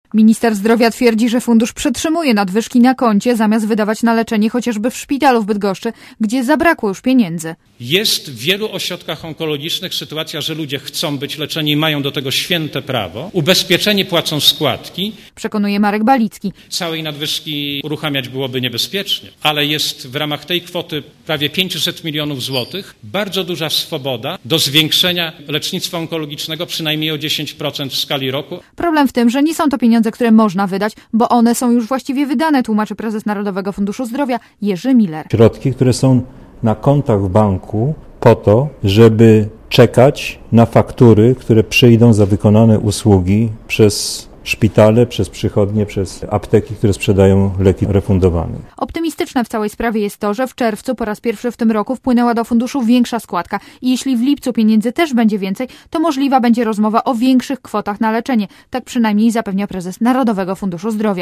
Źródło zdjęć: © Seat 19.07.2005 20:18 ZAPISZ UDOSTĘPNIJ SKOMENTUJ Relacja reportera Radia ZET